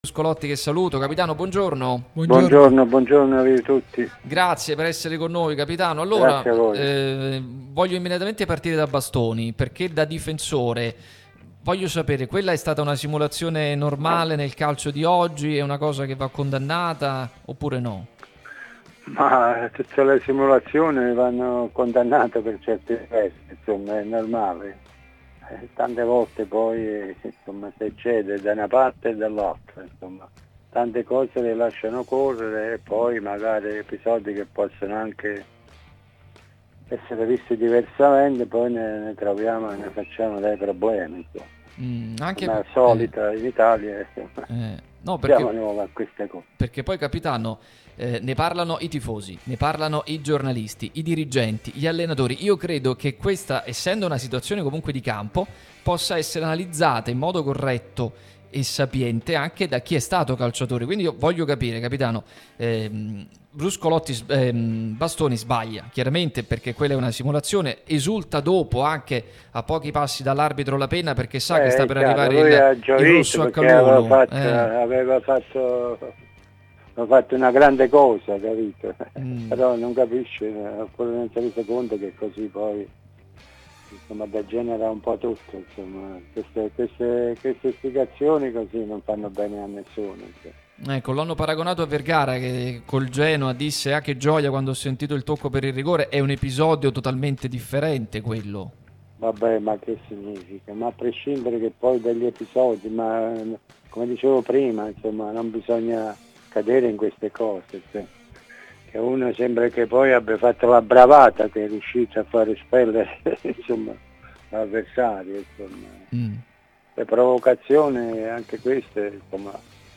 Giuseppe Bruscolotti, ex difensore e storico capitano del Napoli, è intervenuto su Radio Tutto Napoli, prima radio tematica sul Napoli, che puoi seguire sulle app gratuite (scarica qui per Iphone o per Android), qui sul sito anche in video.